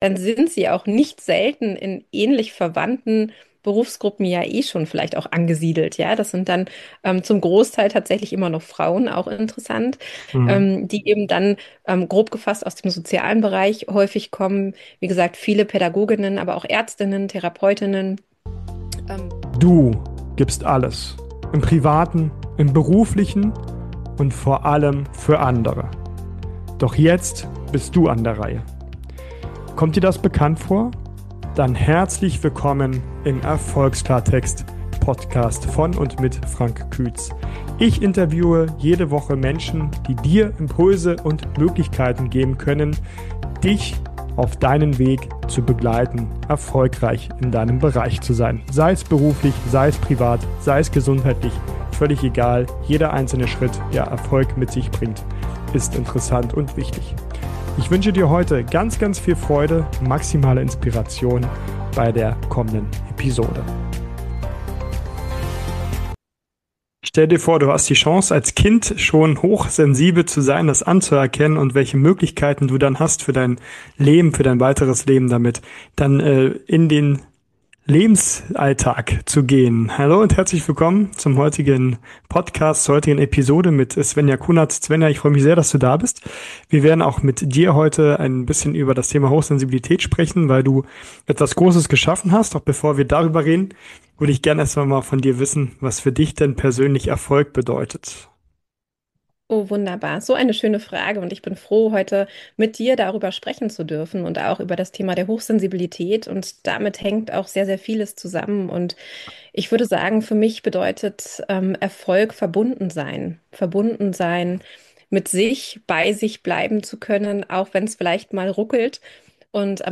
Dieses Interview zeigt eine neue Perspektive auf Hochsensibilität.